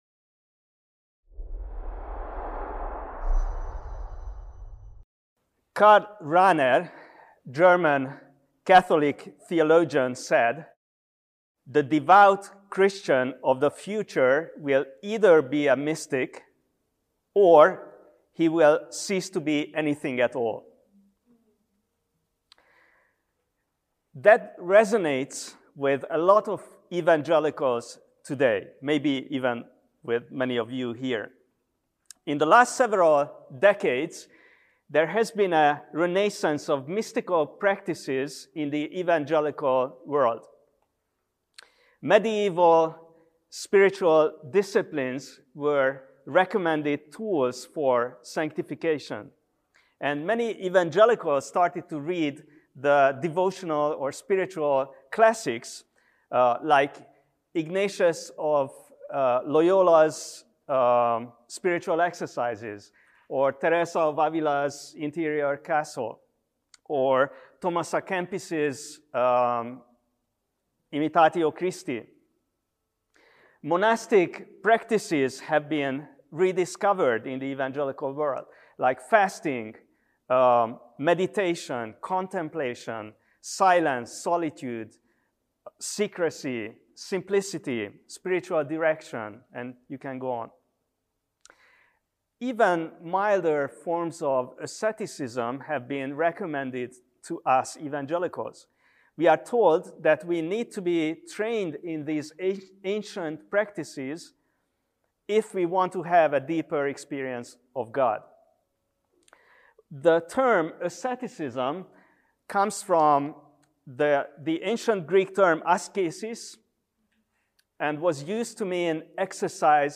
Out of a desire to go deeper in their walks with God, many evangelicals are adopting an understanding of spiritual disciplines and spiritual direction that is grounded in asceticism and mysticism. In this talk, we seek to understand and evaluate the mystical and ascetic traditions, asking honest questions about the true nature of spiritual experience in light of the Gospel of